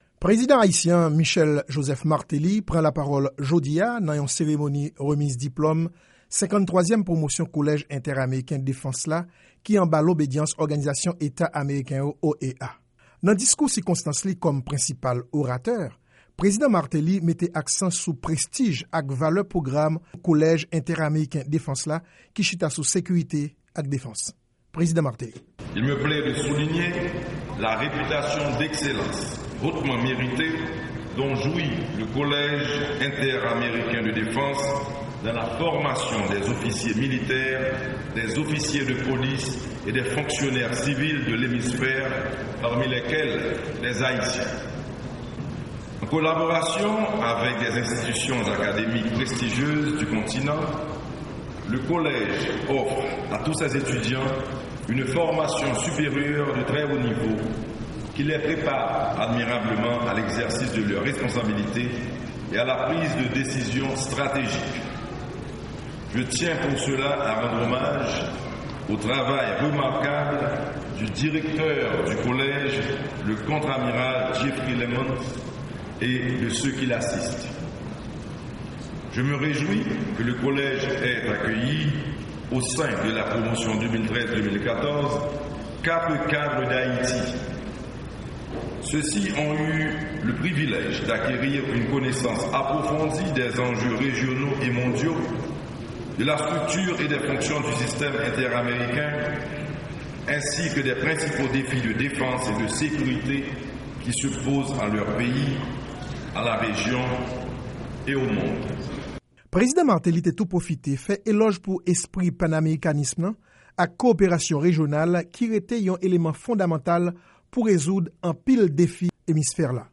Prezidan Martelly pran lapawòl nan l'OEA - Repòtaj